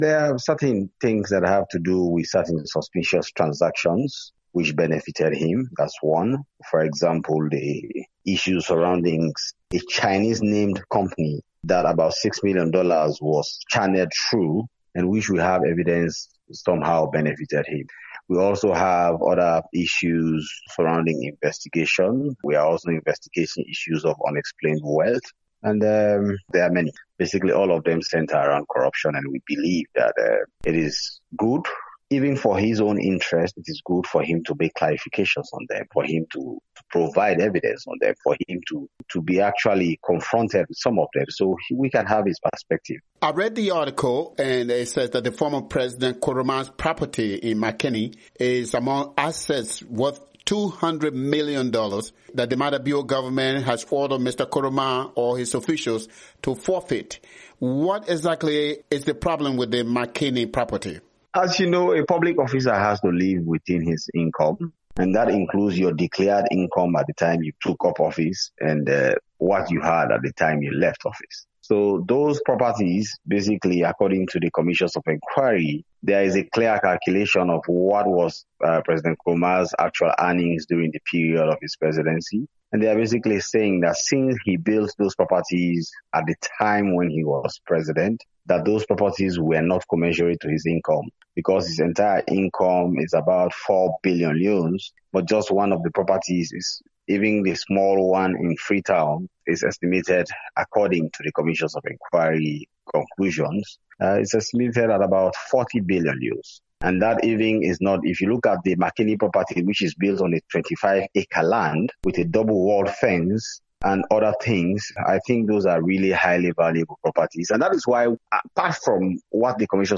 VOA Interview: Francis Ben Kaifala